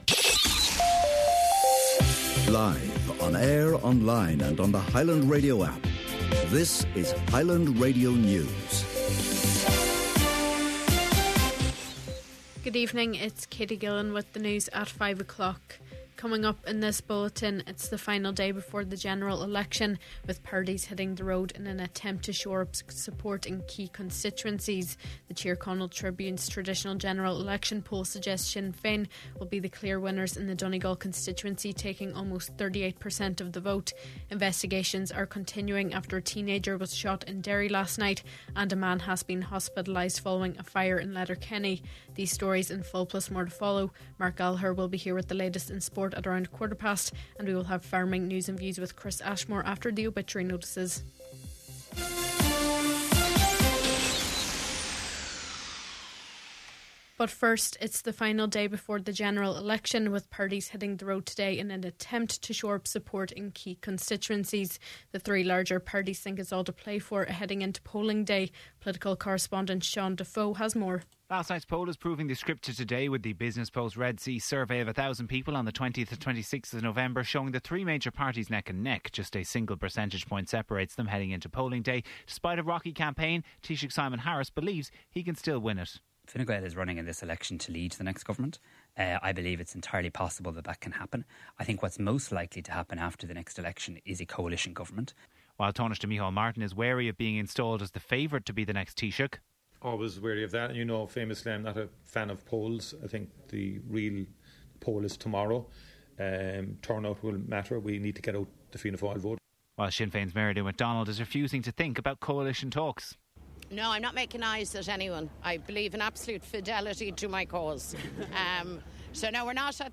Main Evening News, Sport, Farming and Obituaries – Thursday, November 28th